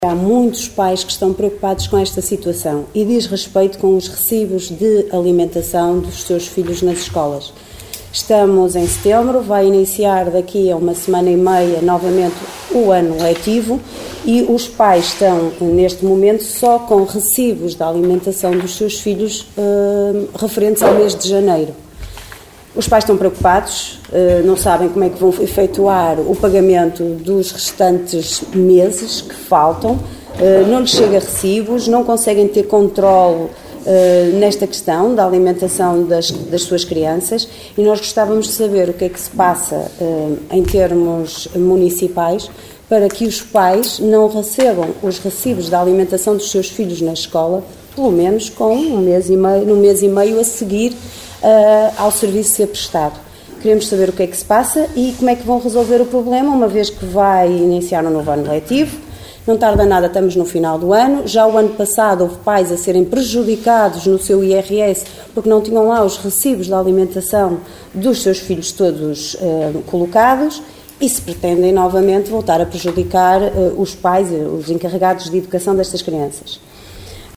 A vereadora da Coligação O Concelho em Primeiro, Liliana Silva, voltou a chamar a atenção do executivo, na reunião de Câmara realizada ontem à tarde (4 de setembro), para o atraso na entrega aos encarregados de educação, dos recibos das refeições dos alunos.